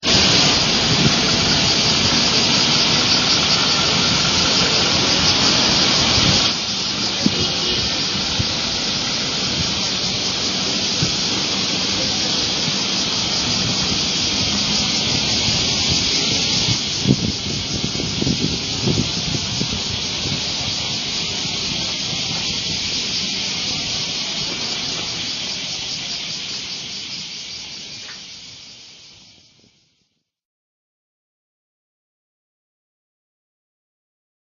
蝉時雨２０１１新富士駅前 １０日遅いかな？